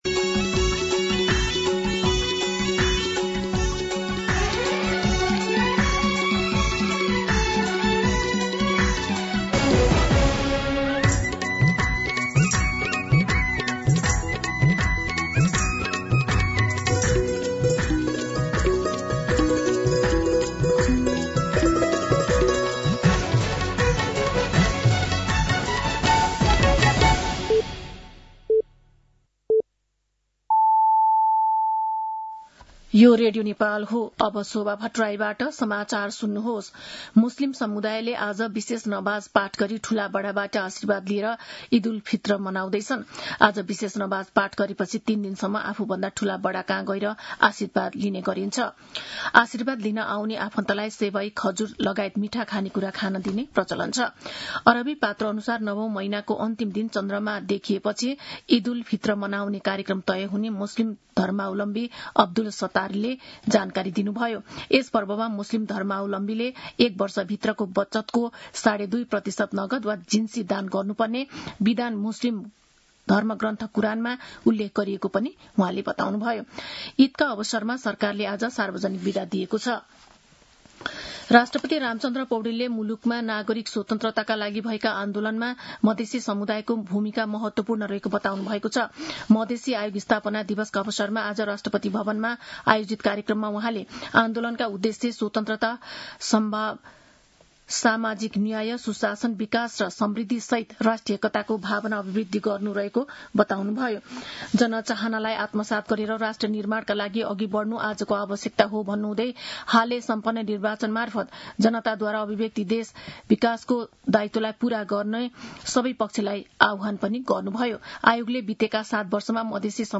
दिउँसो ४ बजेको नेपाली समाचार : ७ चैत , २०८२
4-pm-Nepali-News-4.mp3